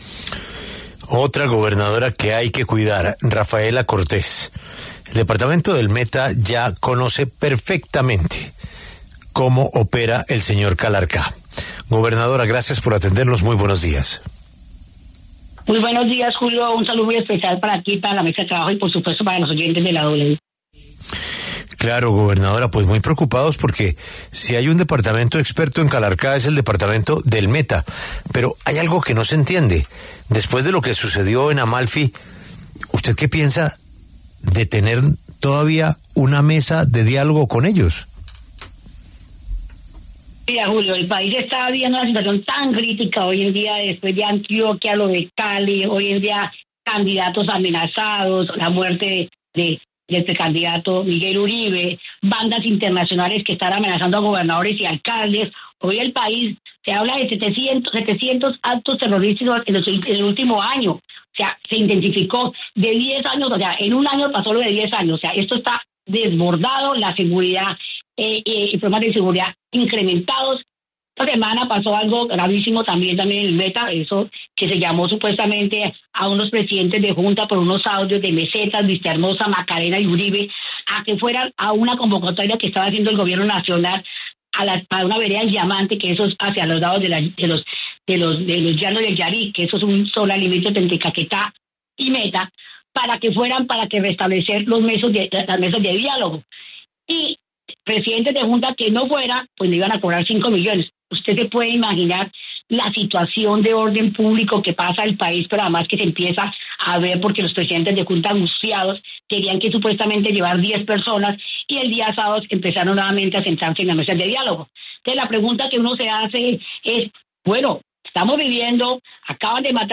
Rafaela Cortés, gobernadora del Meta, habló en La W sobre las acciones de grupos al margen de la ley en su departamento.